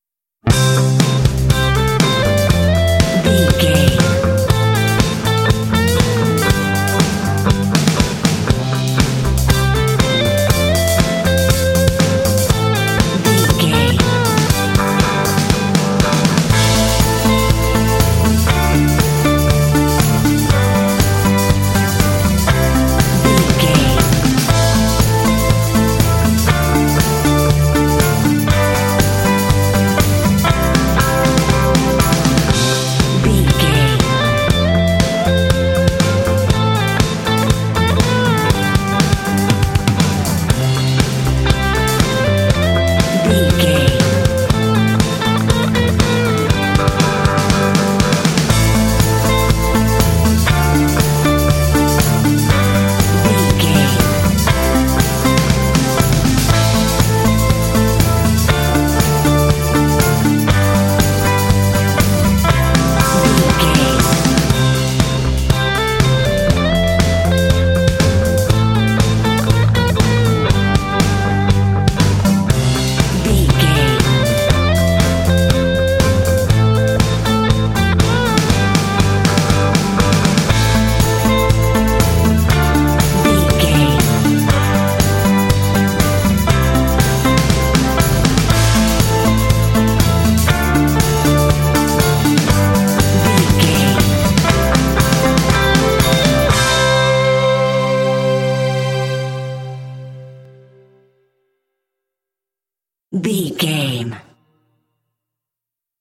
Ionian/Major
driving
bouncy
happy
uplifting
percussion
drums
bass guitar
synthesiser
electric guitar
strings
rock
pop
alternative rock
indie